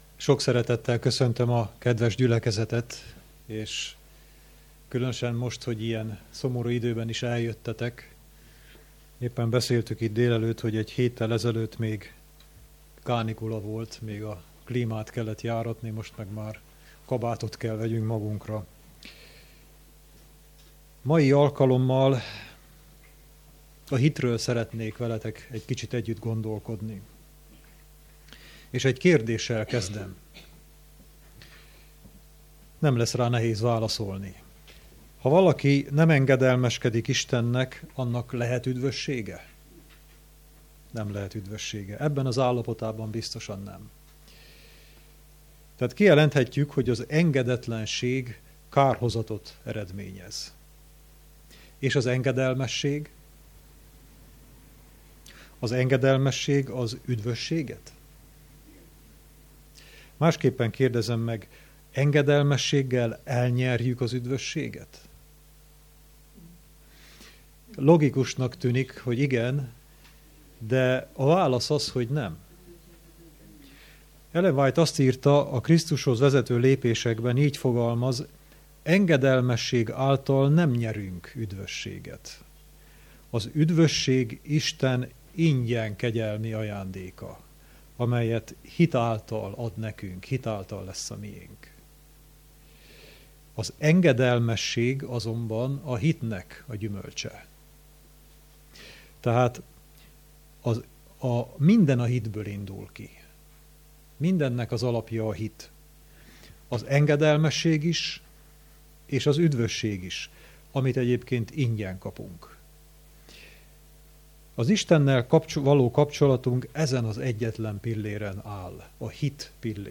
Igehirdetések | Szombati igehirdetés